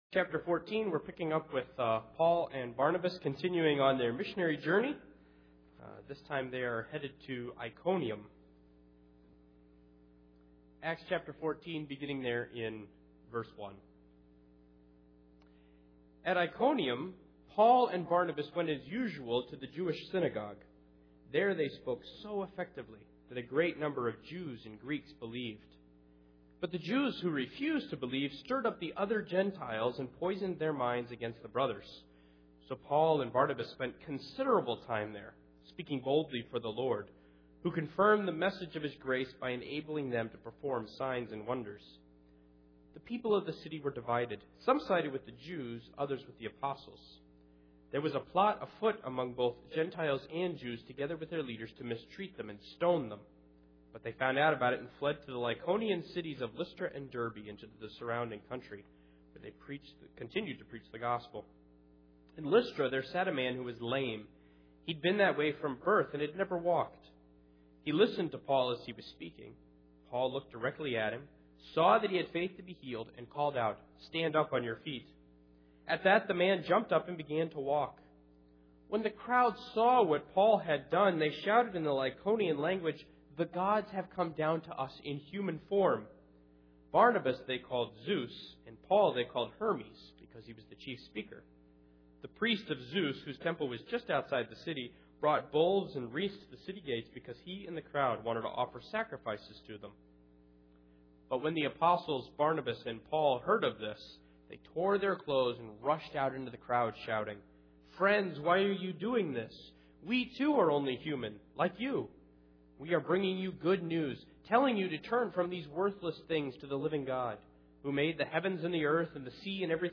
Acts 14:1-20 Service Type: Sunday Morning How do we know about God?